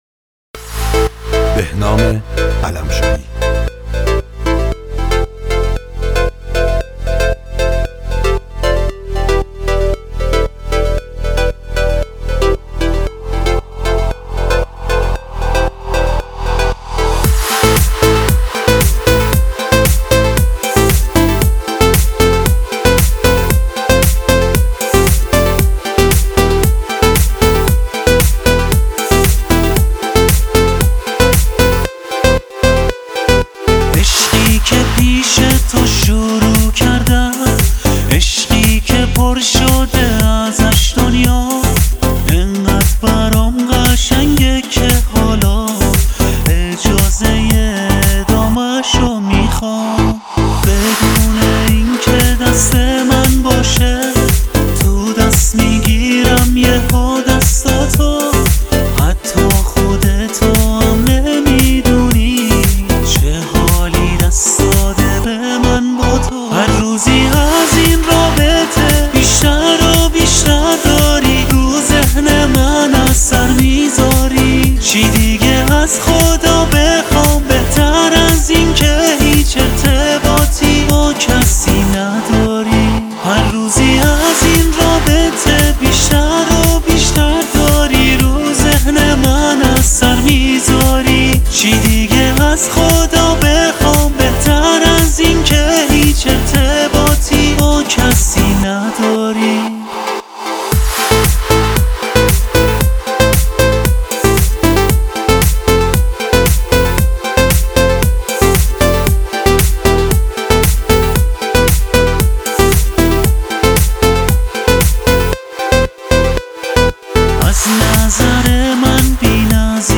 آهنگهای پاپ فارسی
موزیک بی کلام